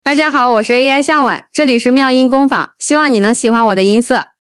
Ai 向晚 少女音 RVC模型
个人自训的模型，并且加了一点点效果在里面，数据集精剪了20来分钟向晚各种状态下的数据，下面展示一下向晚说话以及唱歌方面的表现。